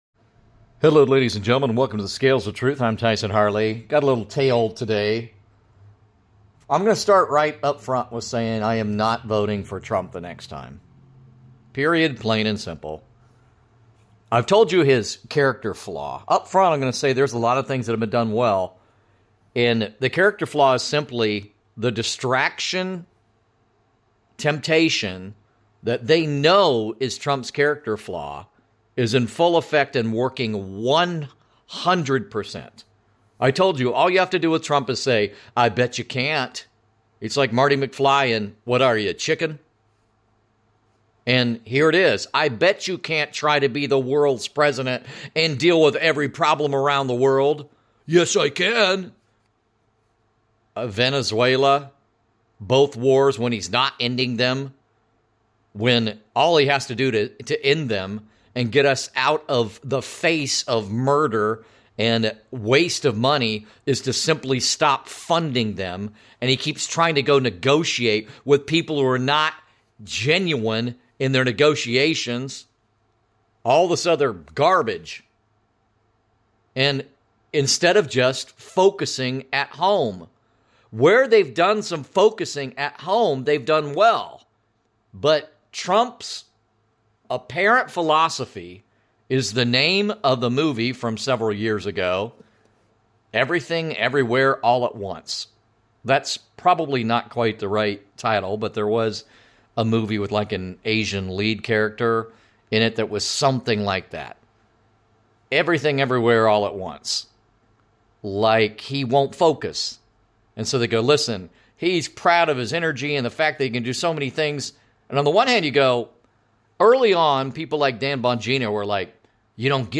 A far-flung rant, granted with justifiably irritated tone, over the latest activities that are NOT about Making America Great Again, and related analysis and topics.